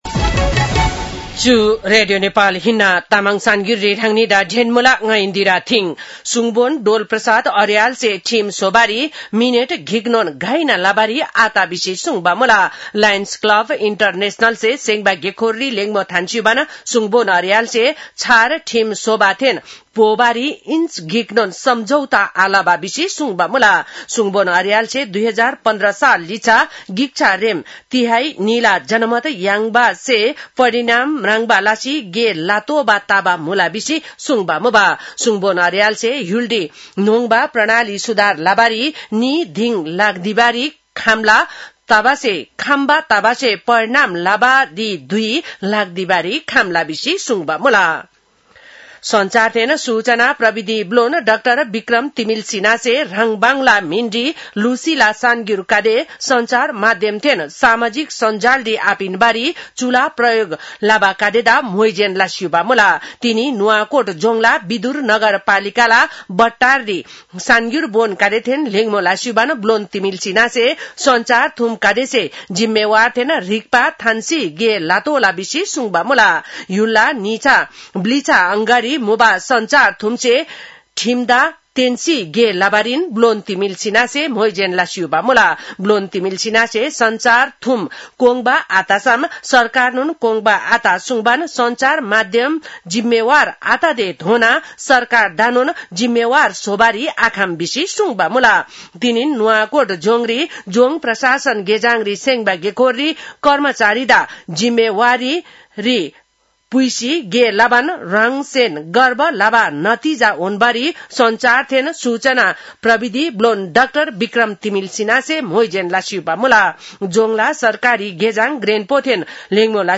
तामाङ भाषाको समाचार : ६ वैशाख , २०८३
Tamang-news-1-06.mp3